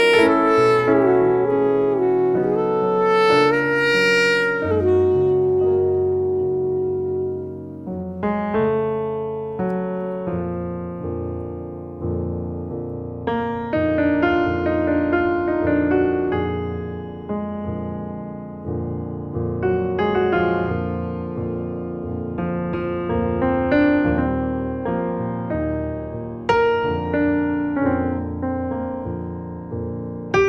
saxophoniste
pianiste virtuose
pièces intimistes
Jazz